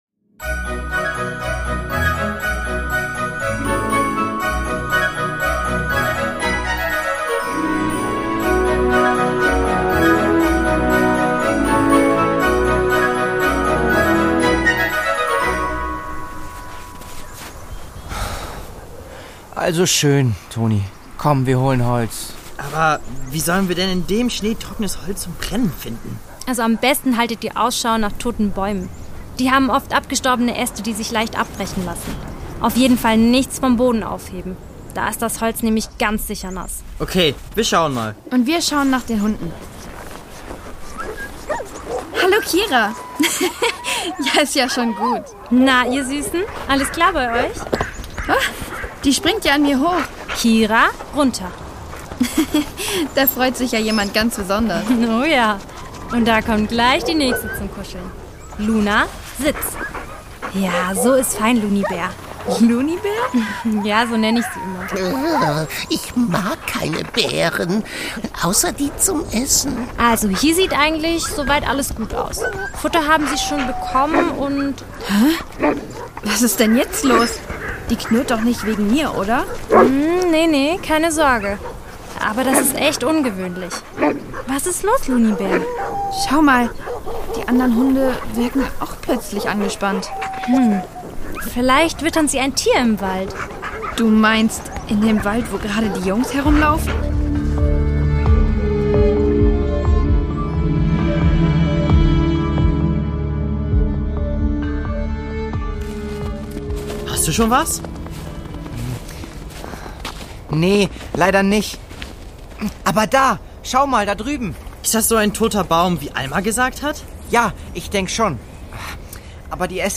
Lappland: Ein Rätsel im Schnee (5/24) | Die Doppeldecker Crew | Hörspiel für Kinder (Hörbuch)